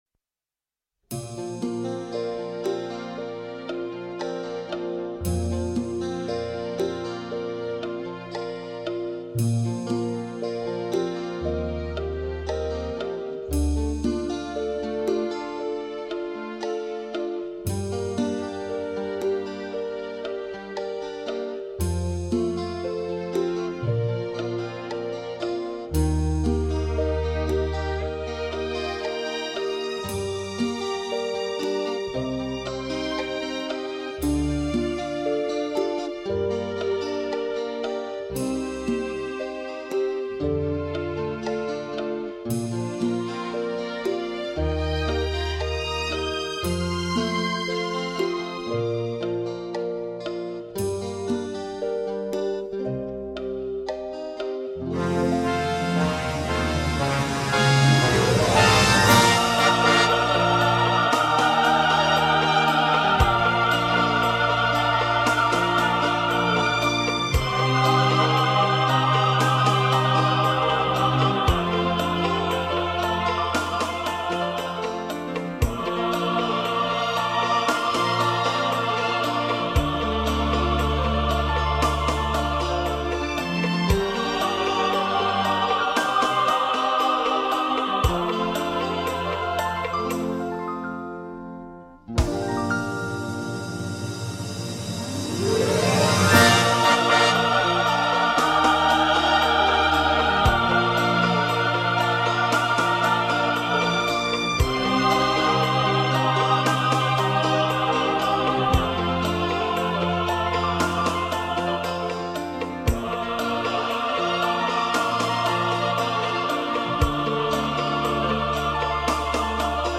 bB调伴奏